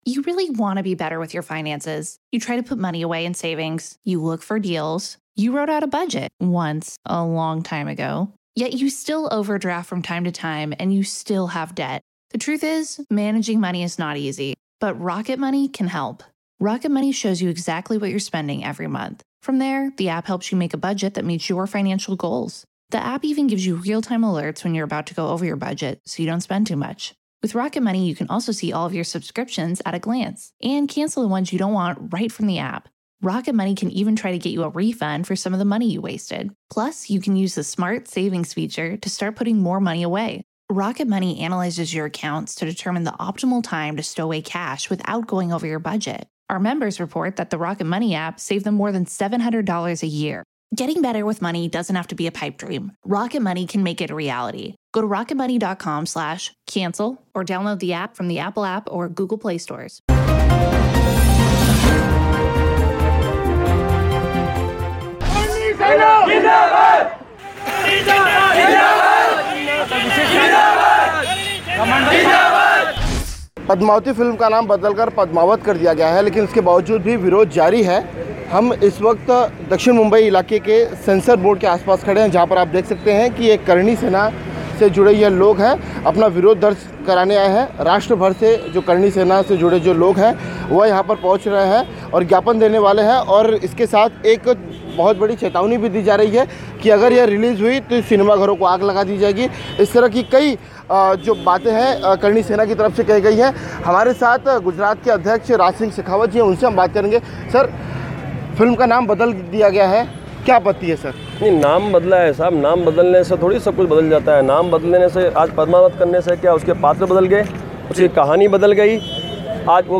News Report / करणी सेना का फिल्म पद्मावत को लेकर विरोध जारी, फिल्म डिवीज़न के दफ्तर का किया घेराव